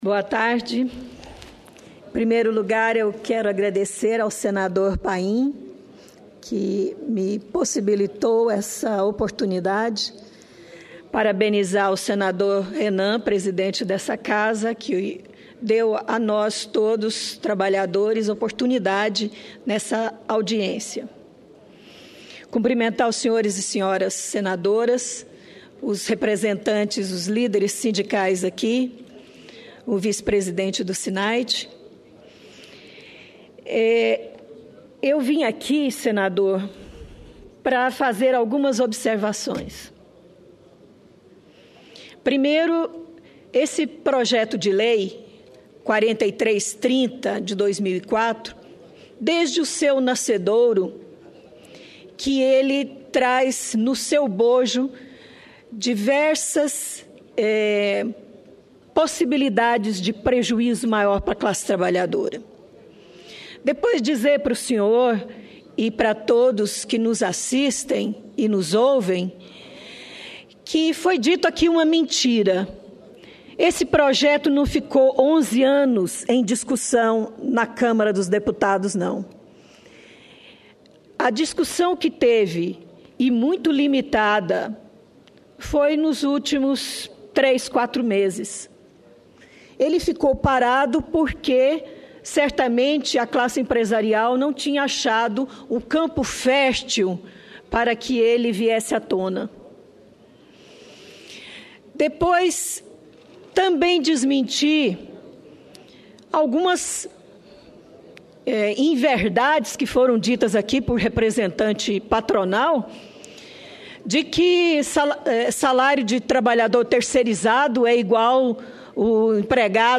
Pronunciamento
Plenário